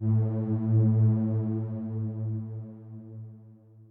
b_basspad_v127l1o3a.ogg